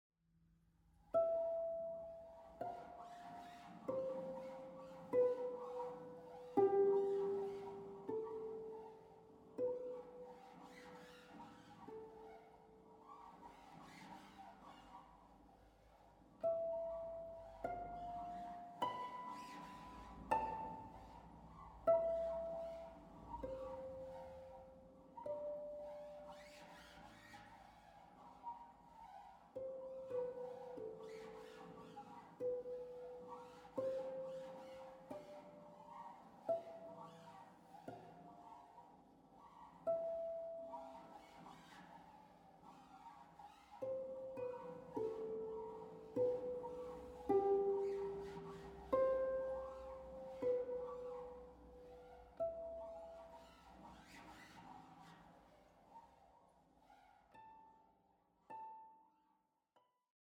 Zeitgenössische Musik für Harfe